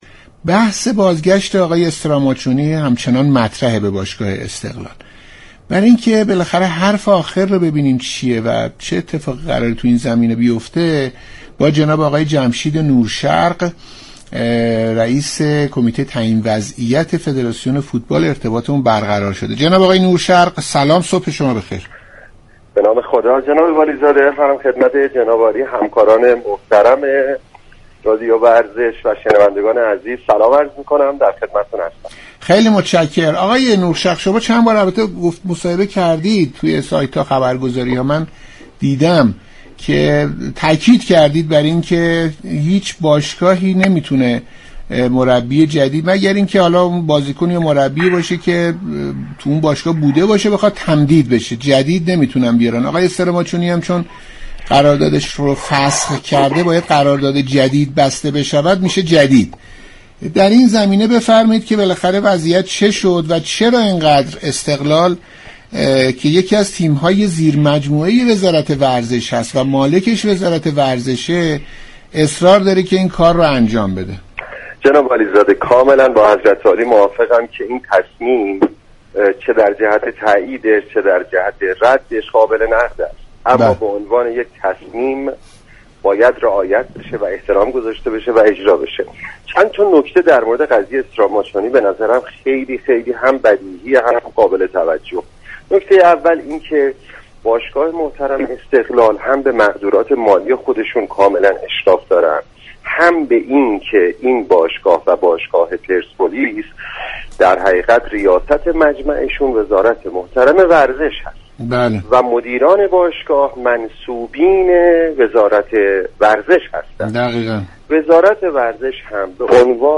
شما می توانید از طریق فایل صوتی پیوست شنونده این گفتگو باشید.
برنامه صبح و ورزش ساعت 7:10 هر روز به جز جمعه ها به مدت یك ساعت و20 دقیقه از شبكه رادیویی ورزش تقدیم شنوندگان می شود.